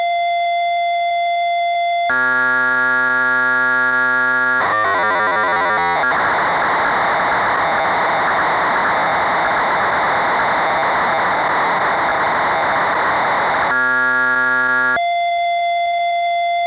C'est en gros le même principe que la maj des télécommandes universelles par téléphone : le fichier est une banale salve de bruit modulé (comme au bon vieux temps du modem analogique).
Poly800_Soundwave_00.wav